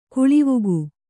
♪ kuḷivugu